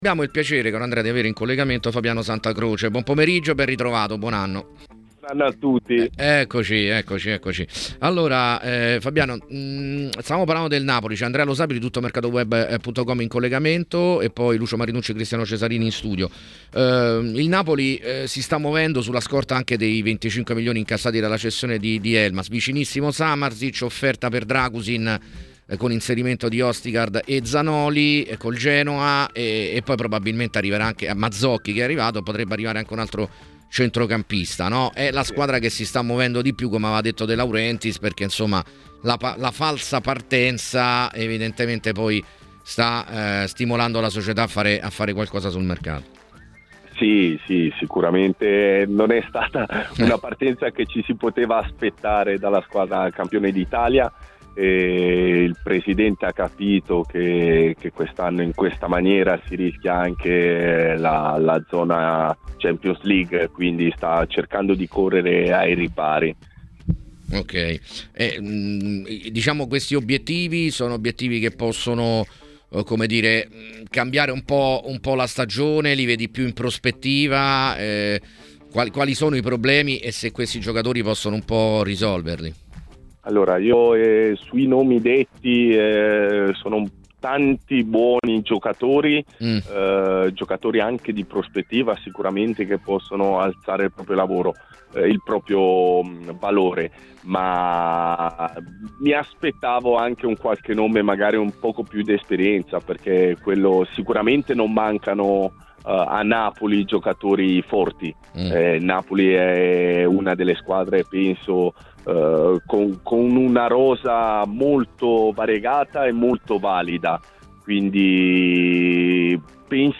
L’ex calciatore ed oggi procuratore Fabiano Santacroce è intervenuto ai microfoni di TMW Radio, durante la trasmissione Piazza Affari, per parlare del momento del Napoli: “Non è stata certamente una partenza che ci si poteva aspettare.